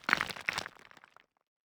UI_StoneRoll_01.ogg